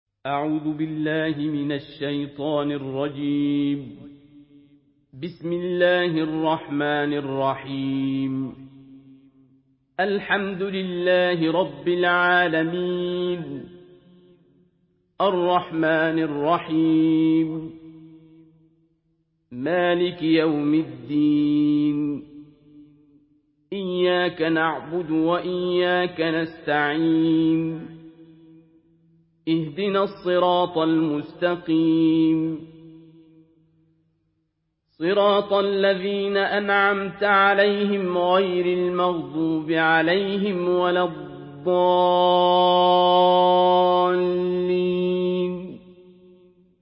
Surah Al-Fatihah MP3 in the Voice of Abdul Basit Abd Alsamad in Hafs Narration
Murattal